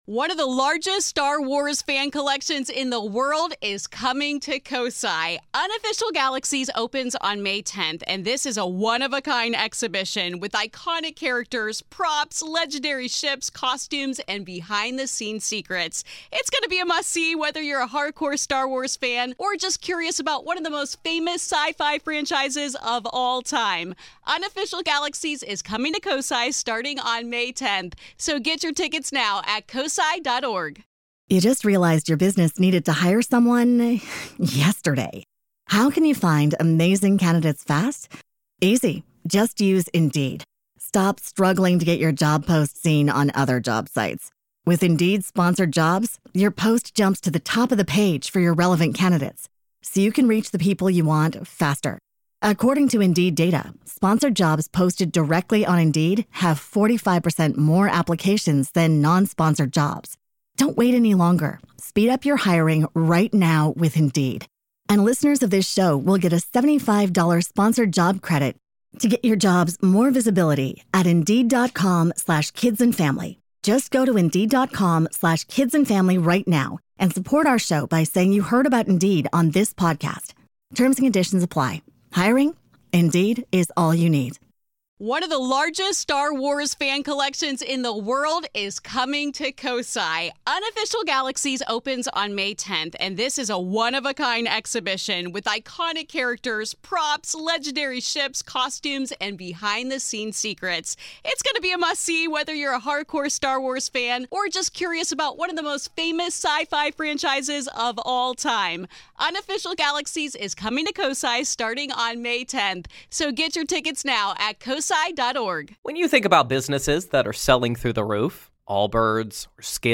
In this harrowing conversation